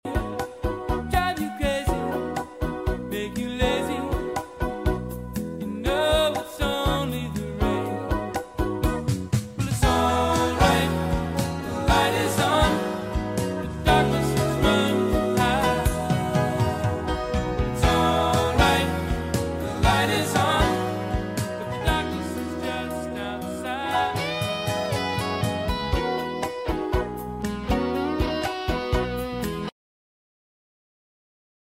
Yacht Rock
Smooth vocals, dreamy melodies